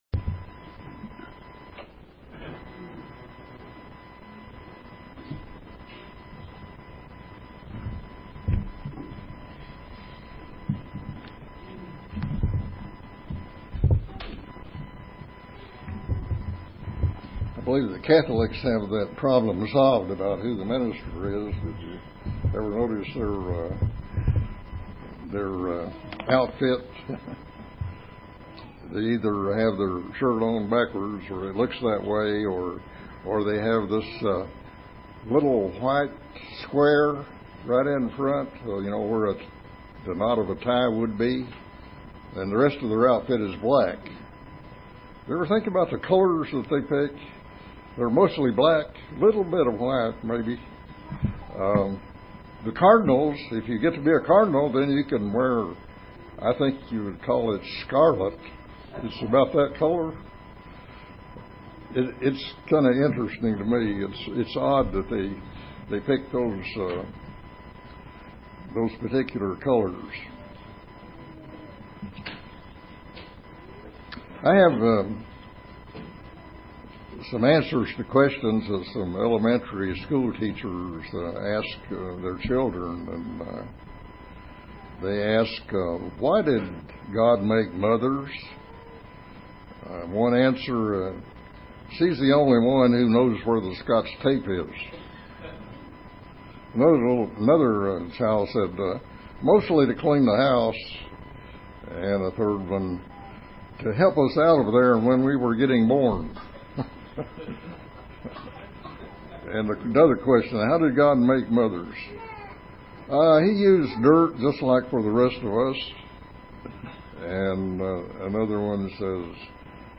Given in Paintsville, KY
UCG Sermon Studying the bible?